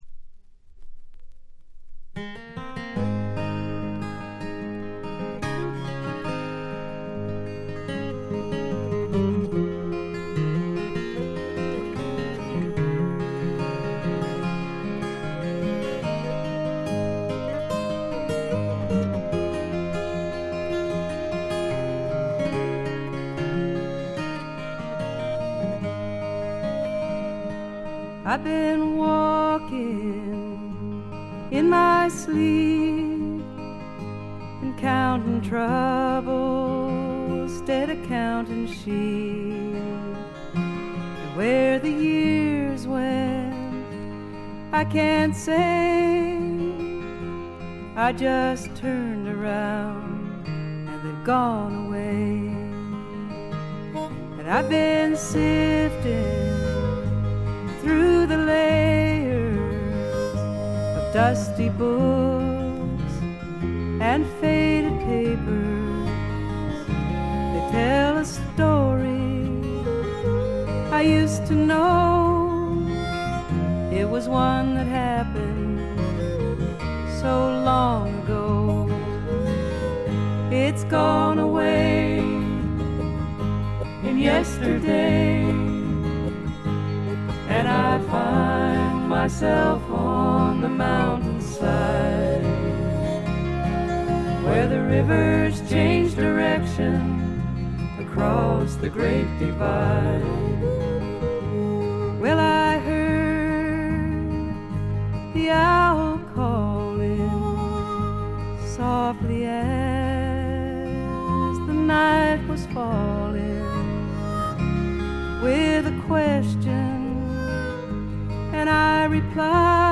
ほとんどノイズ感無し。
本作もしみじみとした歌の数々が胸を打つ女性フォーキー・シンガーソングライターの基本です。
試聴曲は現品からの取り込み音源です。